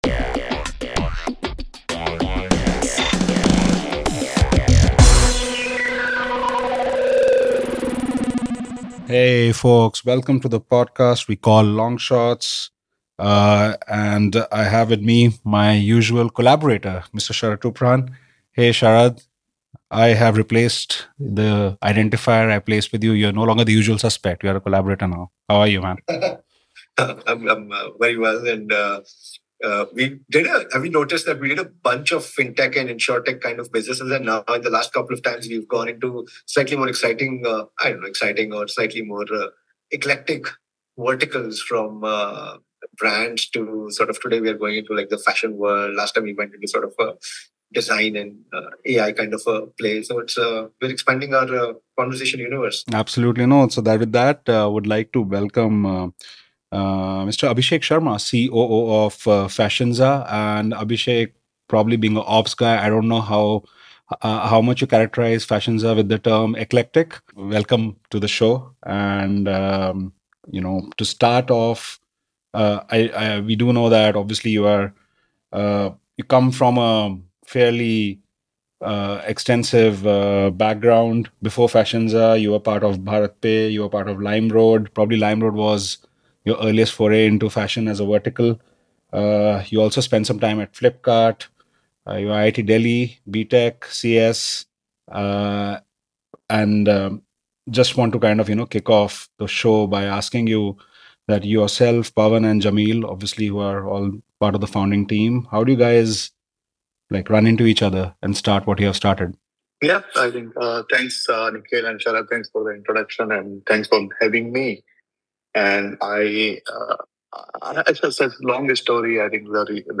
Listen in for a super interesting chat to understand how various cogs work and fit within the global fashion supply chain and how Fashinza is attempting to formalize and optimize this flow.